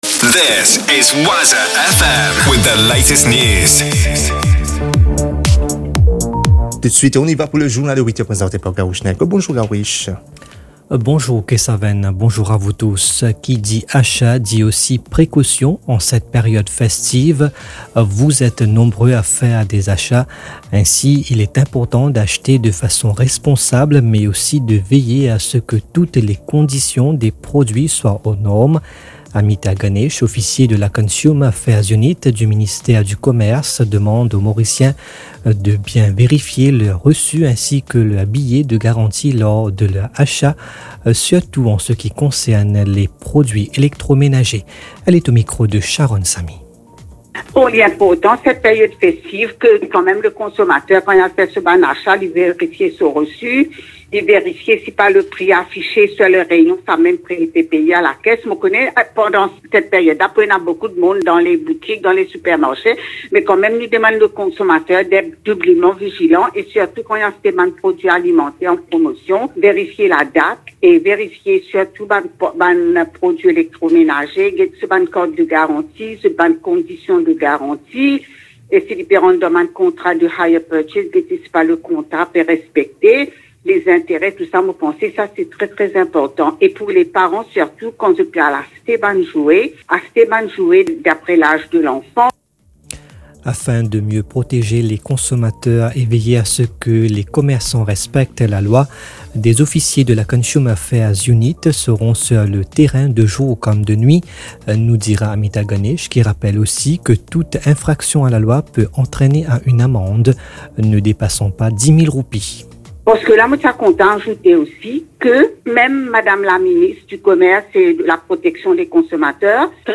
NEWS 8h - 24.12.23